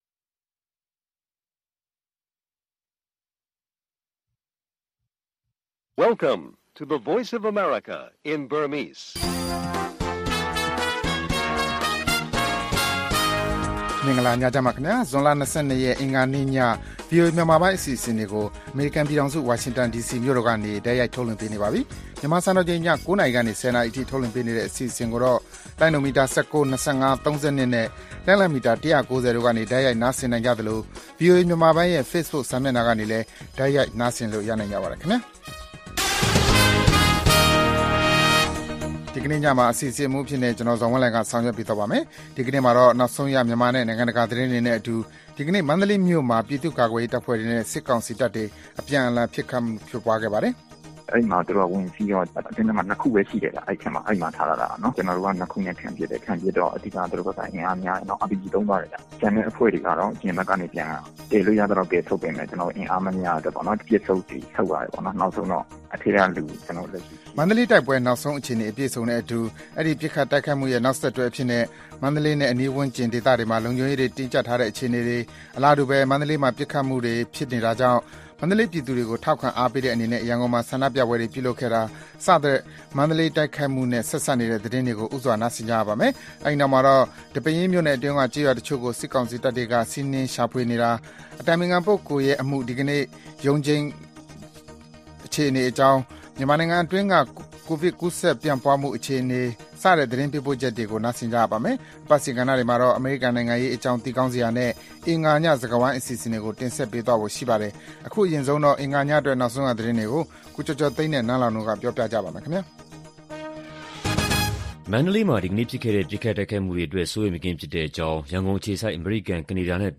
VOA ညပိုင်း ၉း၀၀-၁၀း၀၀ တိုက်ရိုက်ထုတ်လွှင့်ချက်